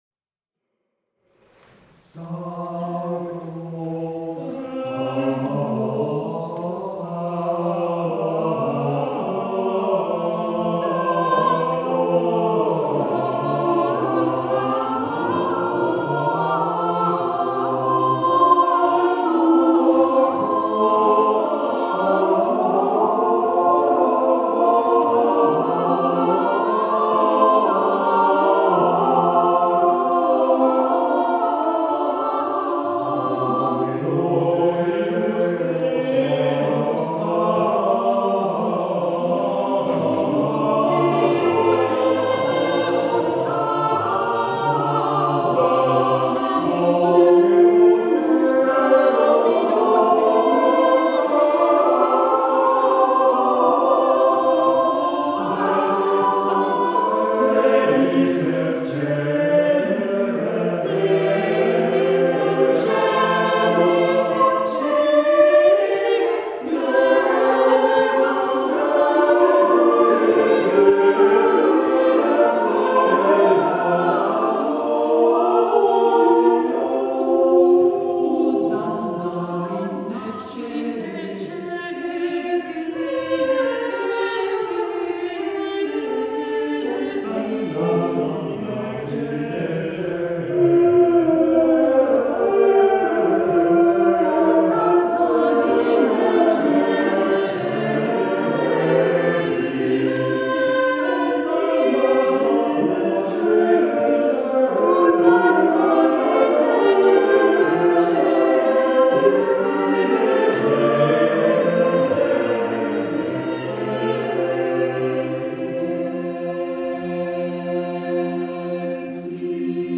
- Samedi 12 juin à 20h00 au temple de Nyon, VD, Suisse.
Un petit extrait de Nyon, live et avec moultes craquements variés :